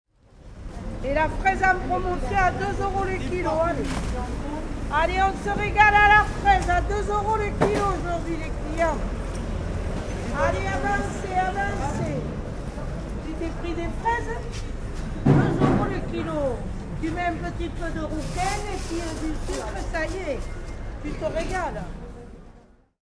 Au petit matin, dans le quartier Saint Michel à Bordeaux, les camions commencent le nettoyage des rues. Le marché des Capucins se réveille.